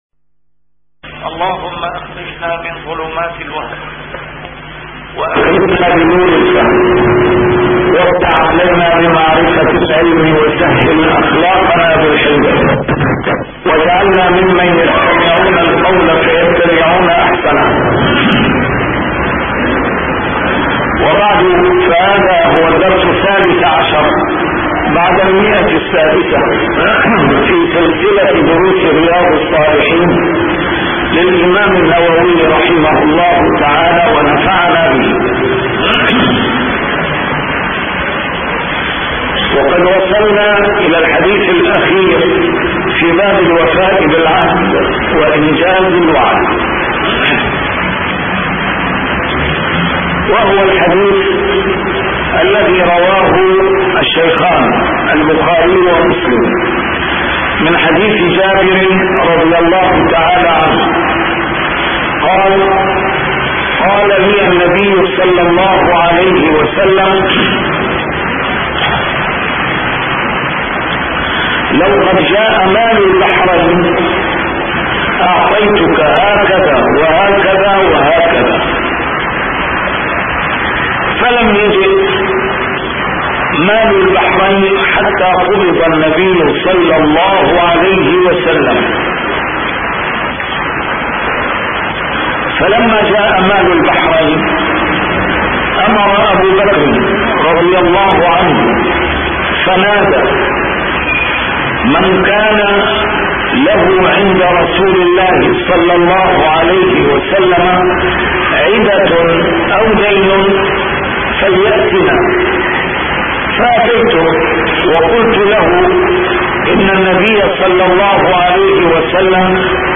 A MARTYR SCHOLAR: IMAM MUHAMMAD SAEED RAMADAN AL-BOUTI - الدروس العلمية - شرح كتاب رياض الصالحين - 613- شرح رياض الصالحين: الوفاء بالعهد وإنجاز الوعد